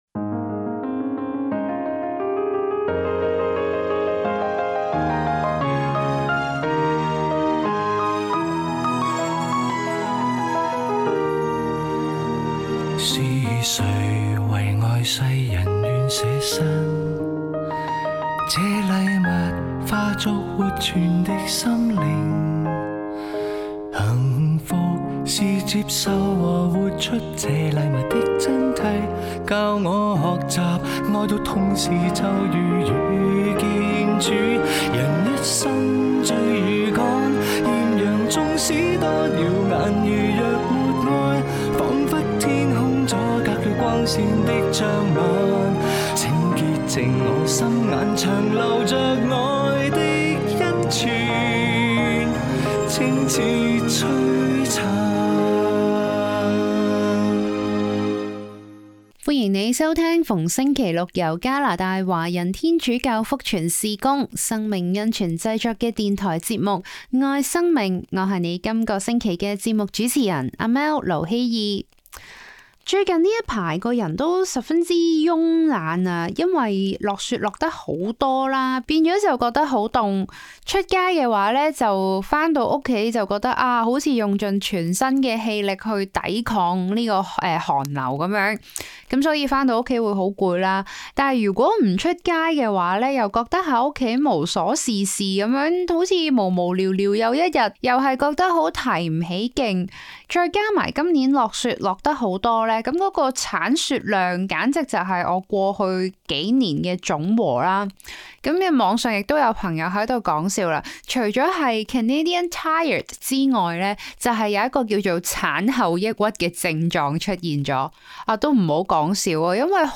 「愛 • 生命」- 二零二六年一月三十一日廣播節目全集 Radio broadcast – Full episode (January 31, 2026)